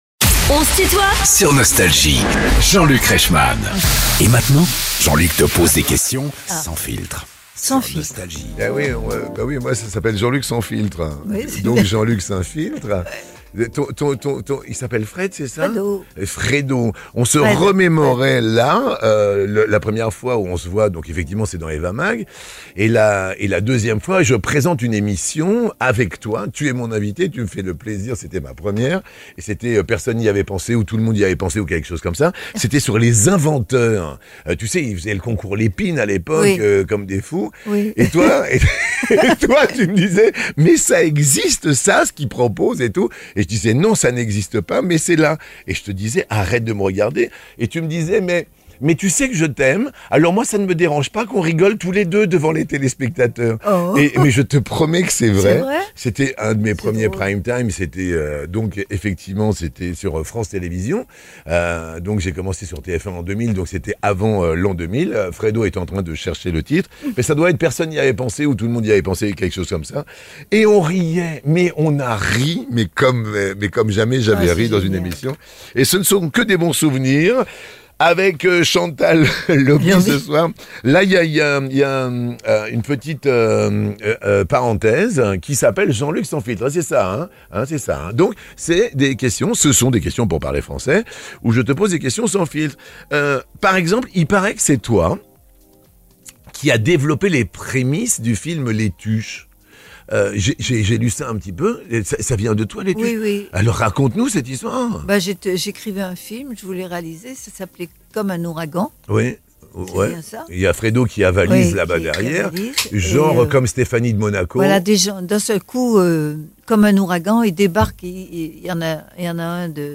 Chantal Lauby est l'invitée de "On se tutoie ?..." avec Jean-Luc Reichmann
Les plus grands artistes sont en interview sur Nostalgie.